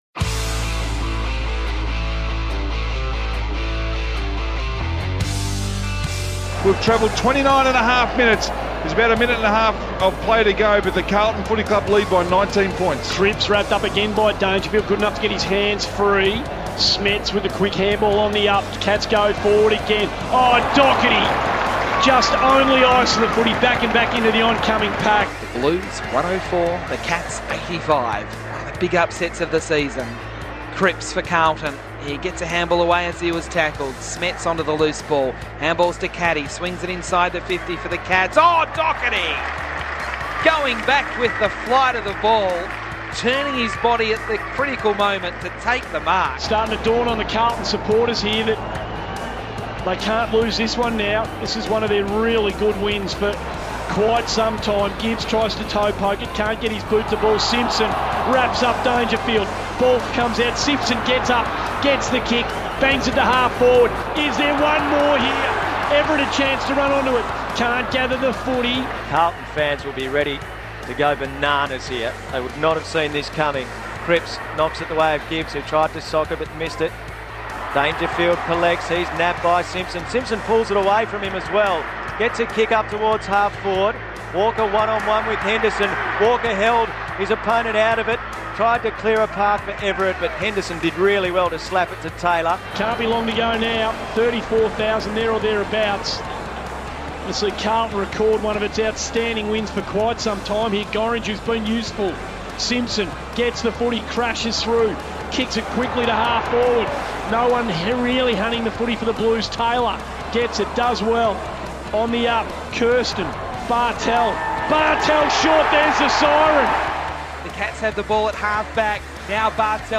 Here's how ABC Grandstand and SEN 1116 called the dying stages of Carlton's gutsy Round 10 victory over Geelong.